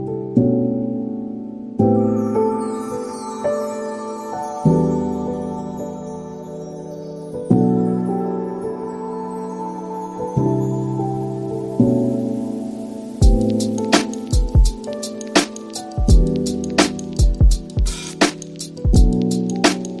Bass Line
# bass # groove # loop About this sound Bass Line is a free music sound effect available for download in MP3 format.
380_bass_line.mp3